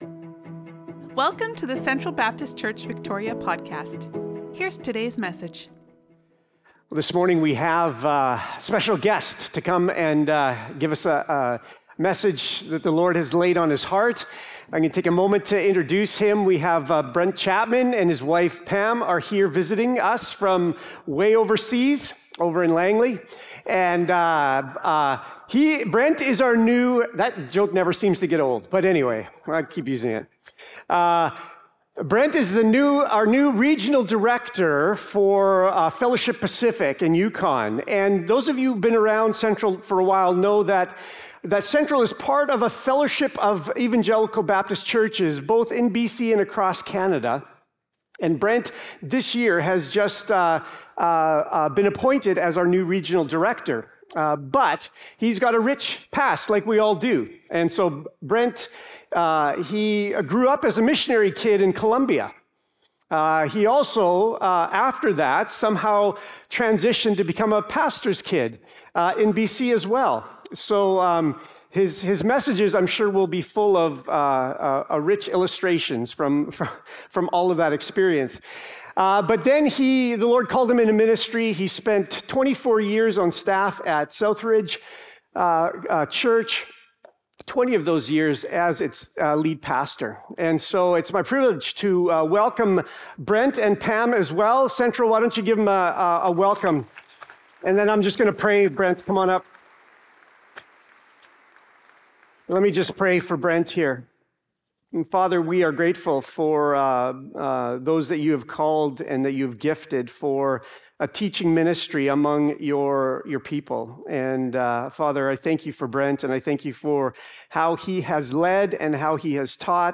Sermons | Central Baptist Church
October 12, 2025 Guest Speaker Download Download Reference 1 Corinthians 1:4-9 Sermon Notes Oct 12'25.Worship Folder.pdf Oct 12'25.Sermon Notes.